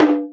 Perc [ Mex ].wav